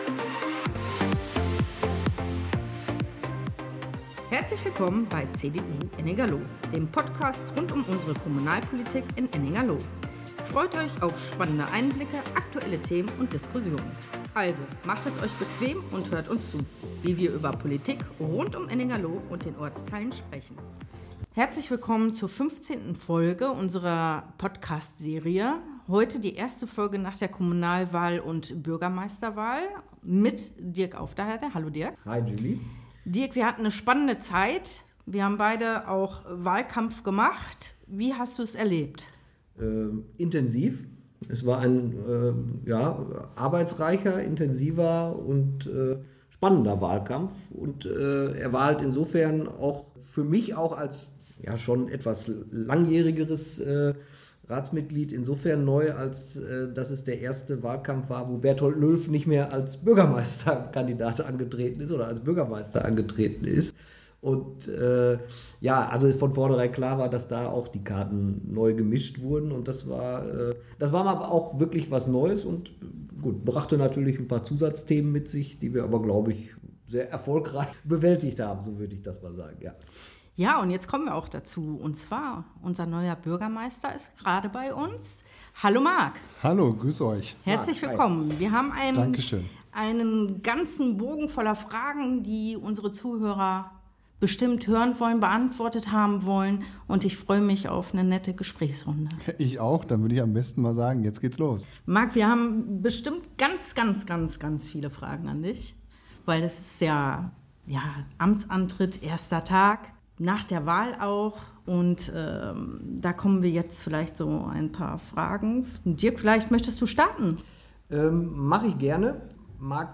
Lively-Instrumental Intro und Outro